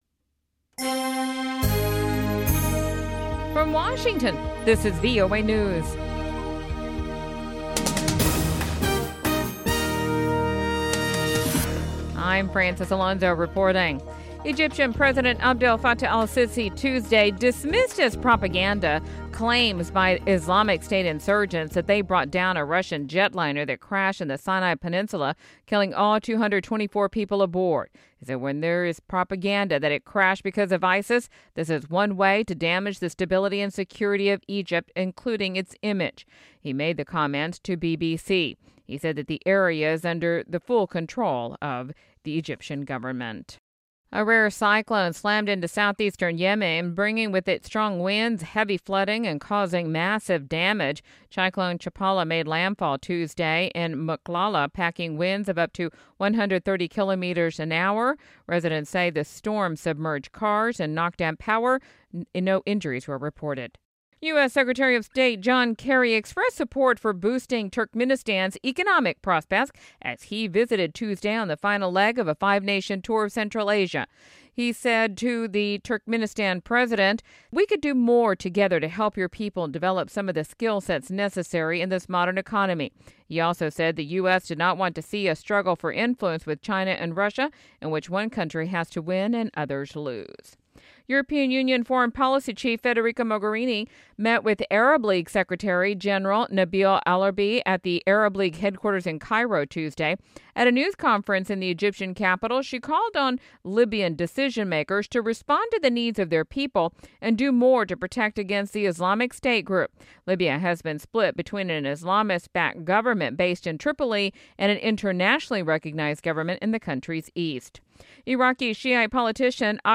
English Newscast: 1900 UTC, Tuesday, November 3, 2015